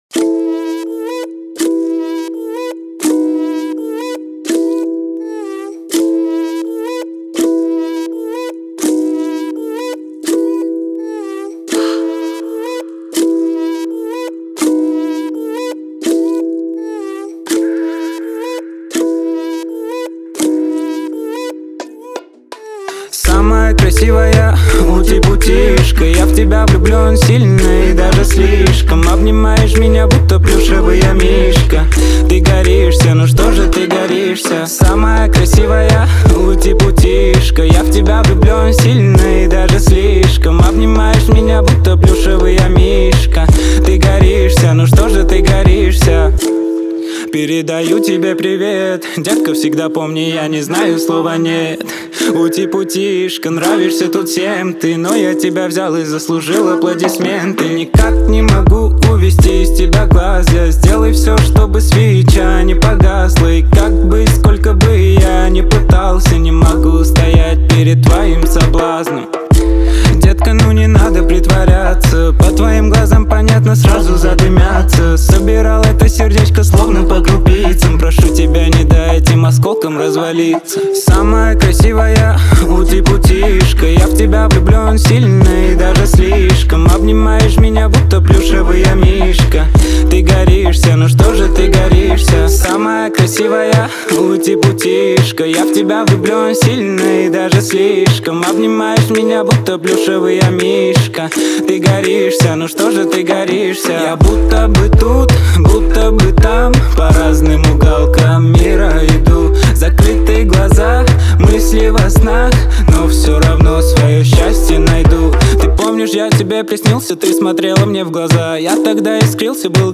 • Жанр: New Rus / Русские песни